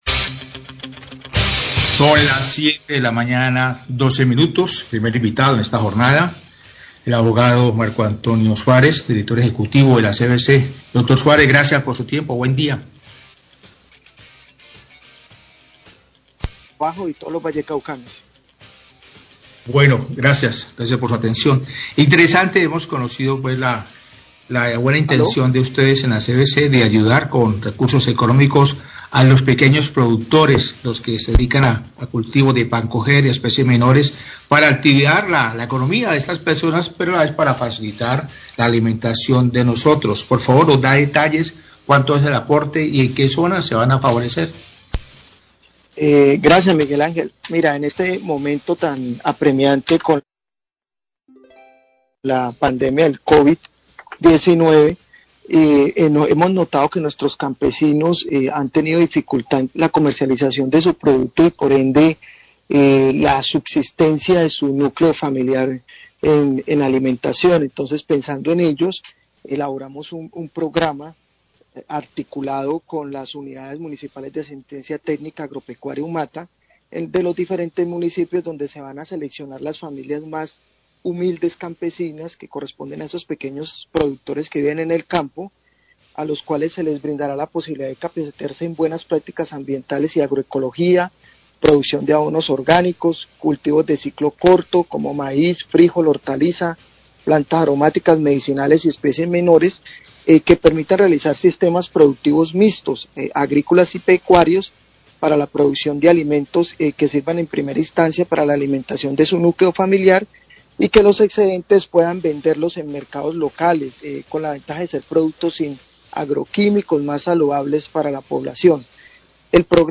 Director de la CVC sobre el proyecto de apoyo a familias campesinas, Noticiero relámpago, 712am
Radio
El director de la CVC, Marco Antonio Suárez, habló sobre el apoyo y capacitaciones que darán a familias campesinas, luego de identificar las dificultades que atraviesan los pequeños productores por la emergencia sanitaria.